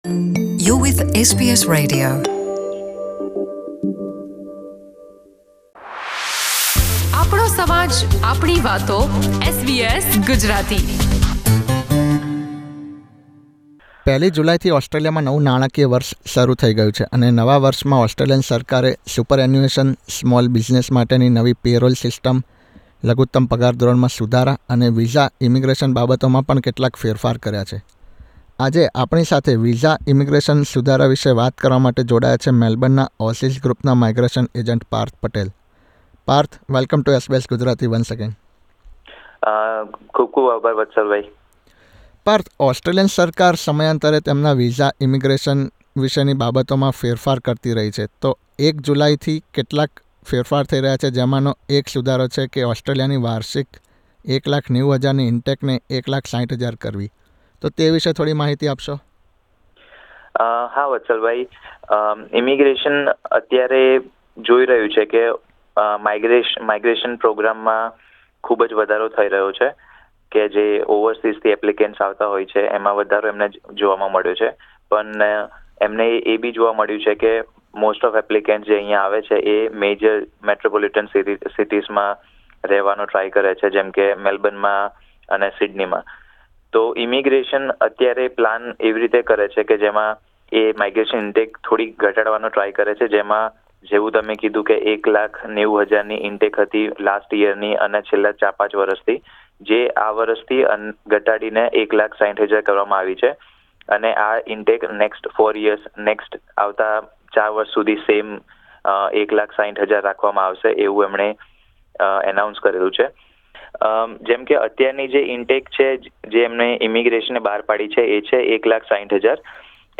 SBS Gujarati સાથે વિશેષ વાતચીત કરી હતી.